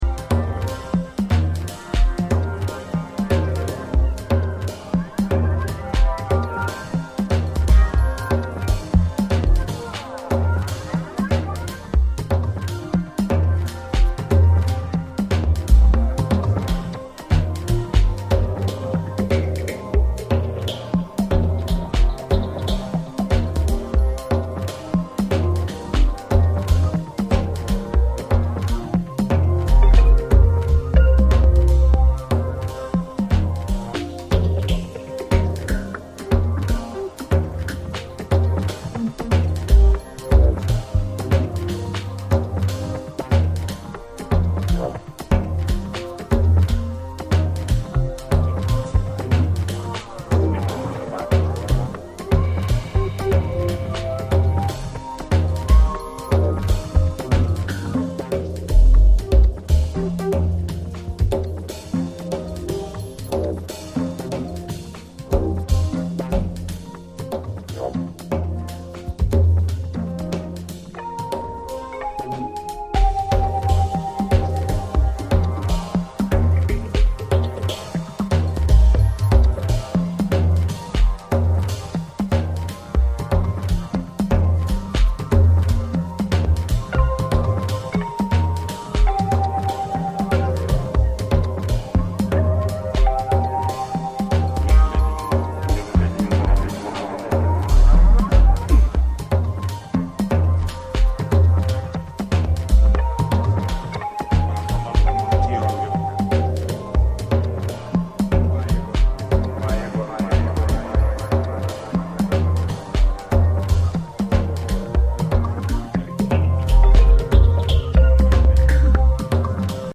captivating tones of Senegalese vocalist